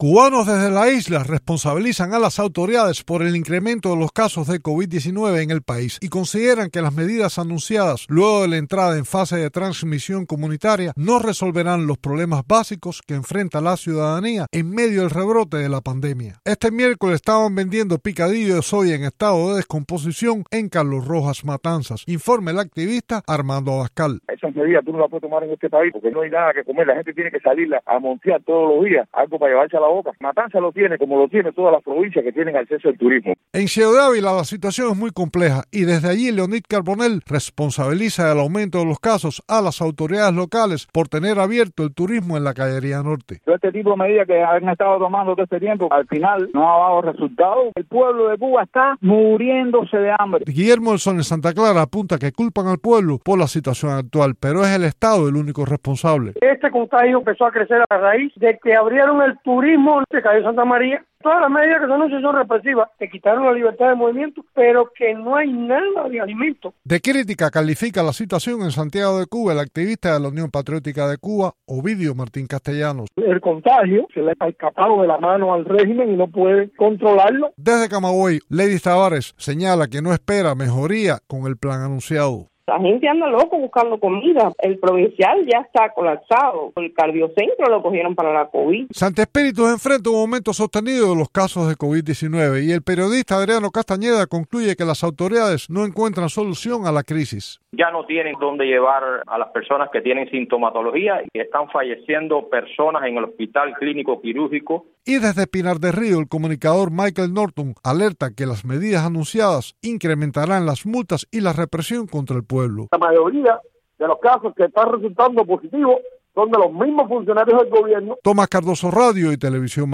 recogió testimonios desde la isla